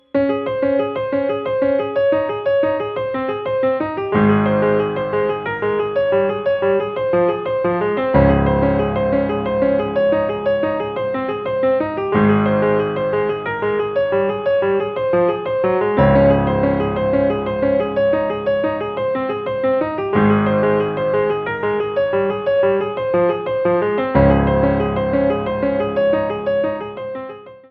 Categories: Piano